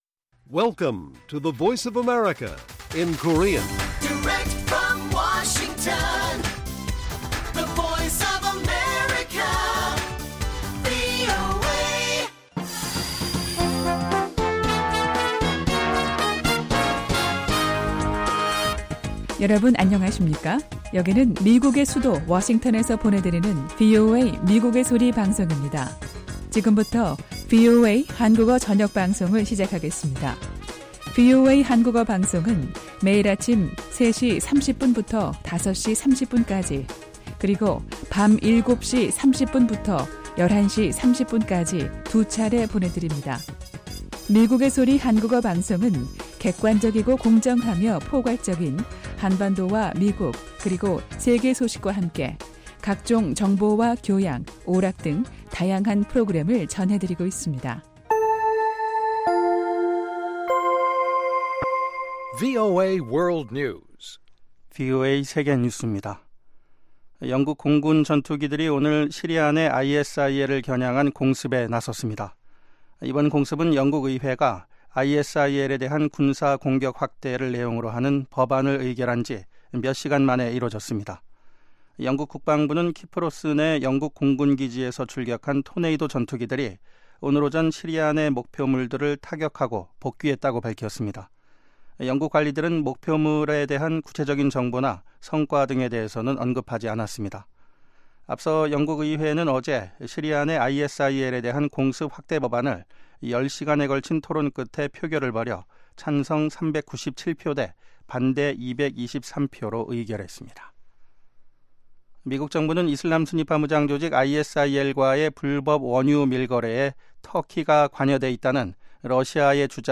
VOA 한국어 방송의 간판 뉴스 프로그램 '뉴스 투데이' 1부입니다.